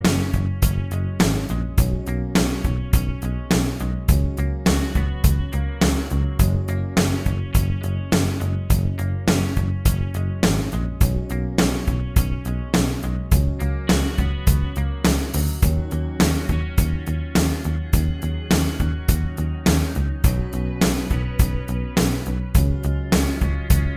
Minus Lead Guitar Pop (1980s) 4:05 Buy £1.50